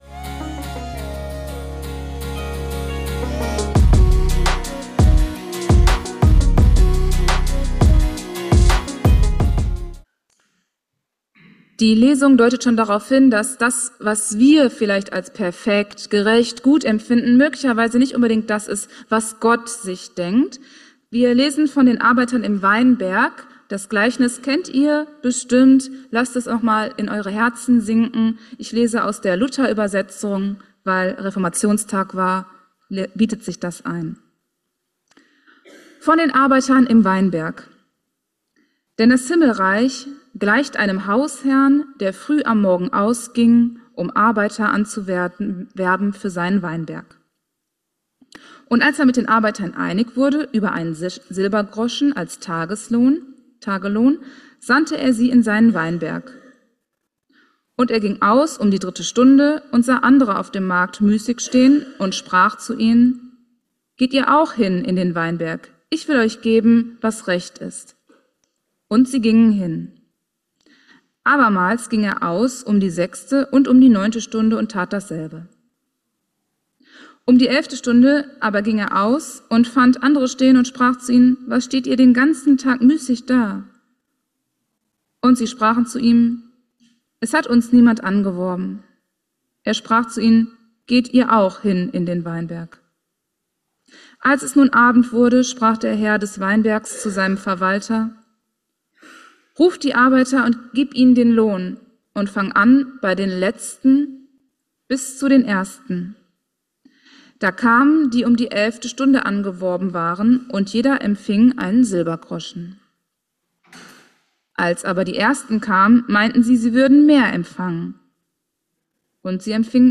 DAS PERFEKTE LEBEN: Geschenk oder Verdienst? ~ Geistliche Inputs, Andachten, Predigten Podcast
Er gab in gewohnt ansprechender Weise Antworten auf diese Frage.